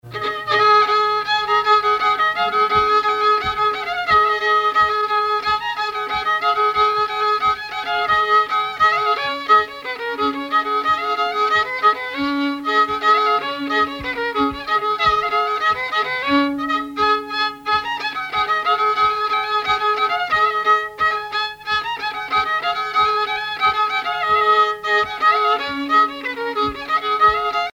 Mémoires et Patrimoines vivants - RaddO est une base de données d'archives iconographiques et sonores.
Rigodon au violon
violoneux, violon, ; musicien(s) ; musique traditionnelle
danse : rigaudon
Pièce musicale inédite